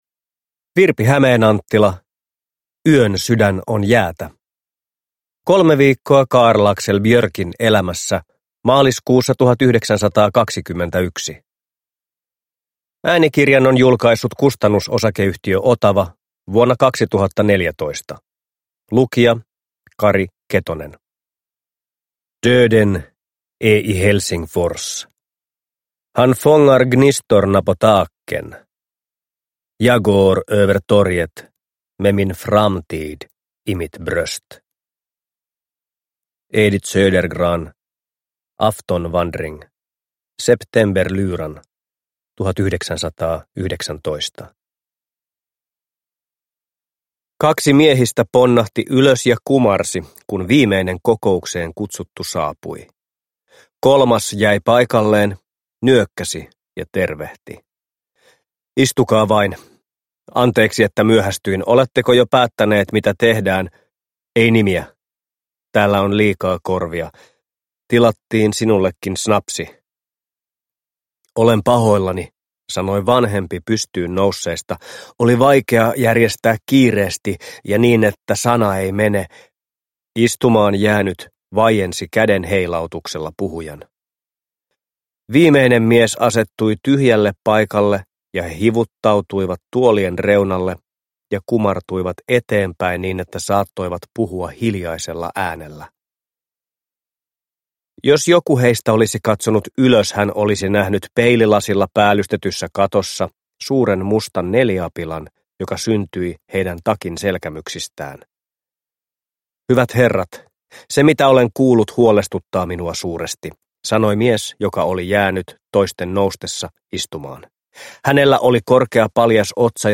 Yön sydän on jäätä – Ljudbok